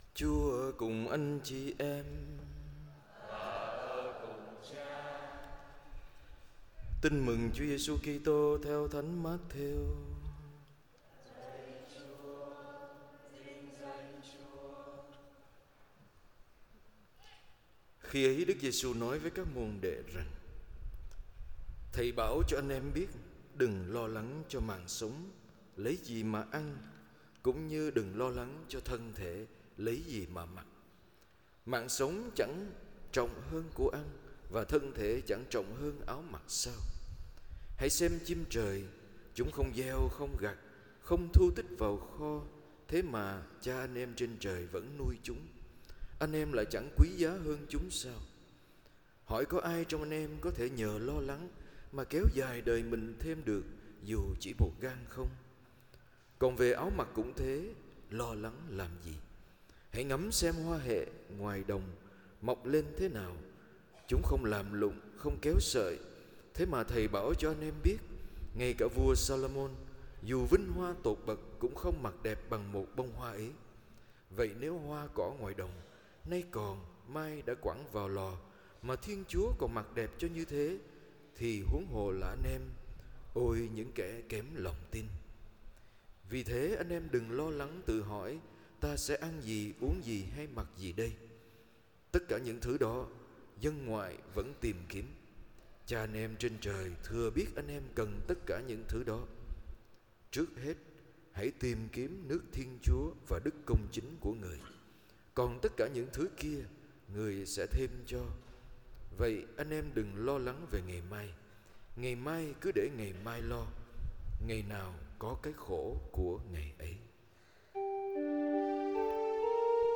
BaiGiang_ThanhLeMongMotTetKyHoi.mp3